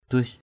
/d̪uɪ’s/ (d.) tội = péché. thei lac ukhin, duis nan nyu ndua E] lC uA{N, d&{X nN v~% Q&% ai nói đó là điều cấm kỵ, tội đó nó mang....
duis.mp3